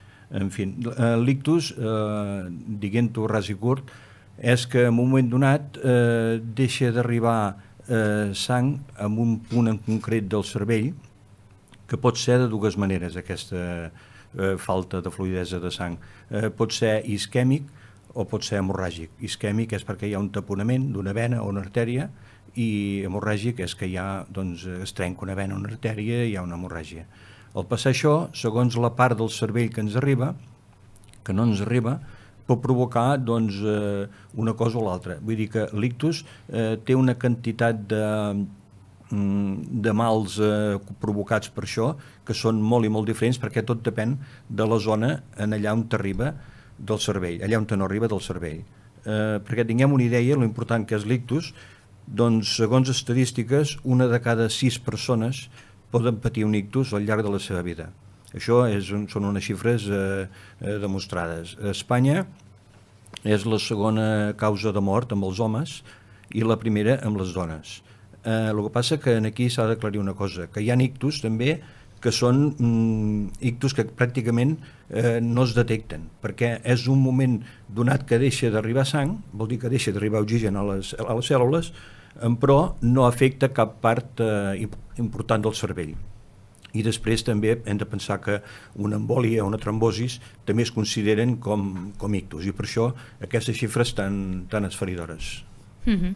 han compartit en una entrevista a Ràdio Capital els seus testimonis personals sobre què suposa patir un ictus i com es pot continuar vivint amb aquesta nova realitat.